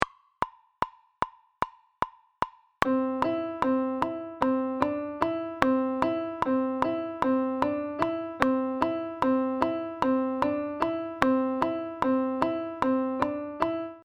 Ejemplo de compás de 7x8 con la medida de 2+2+3.
Audio de elaboración propia. Subdivisión métrica del compás 7x8 en 2 + 2 + 3. (CC BY-NC-SA)
COMPAS-7x8-3.mp3